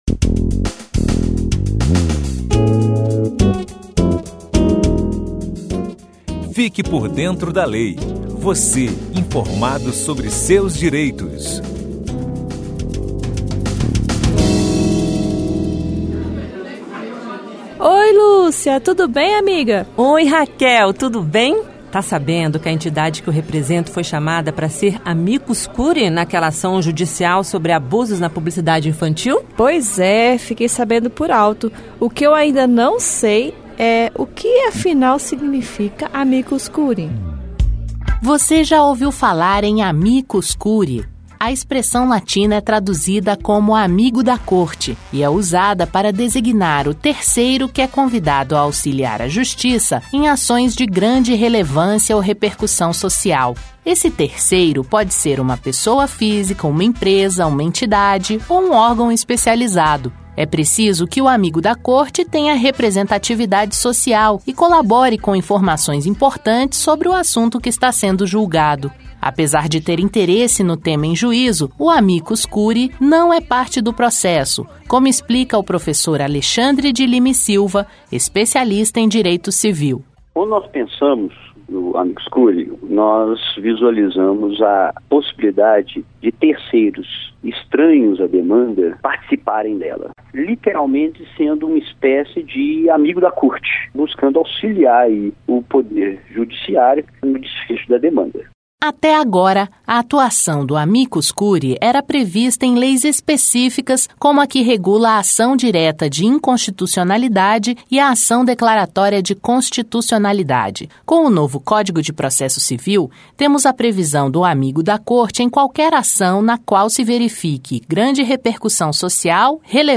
Amicus Curiae Entrevista com o especialista em Direito Civil